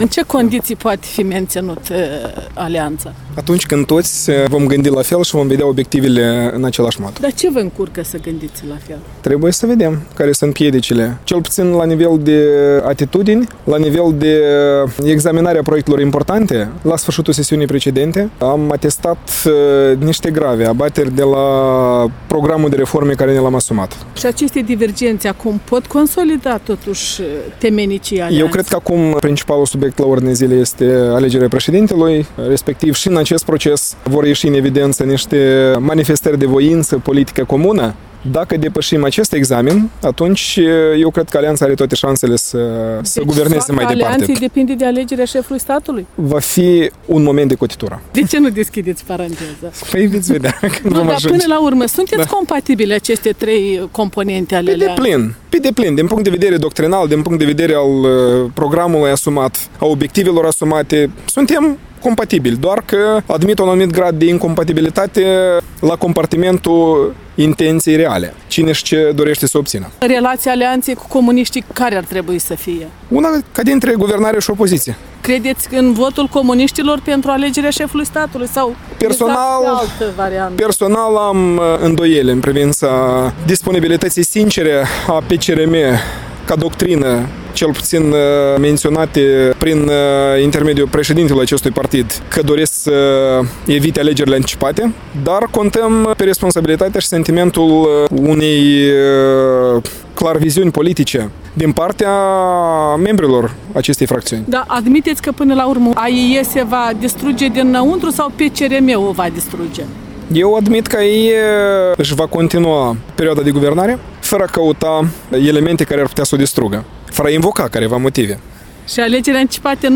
în dialog cu Vasile Streleț (PLDM)